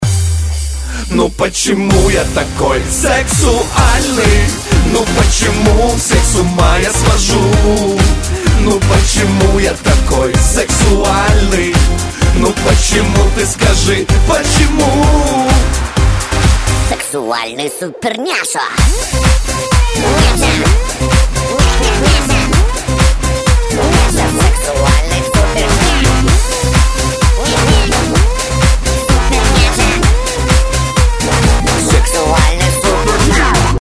• Качество: 128, Stereo
веселые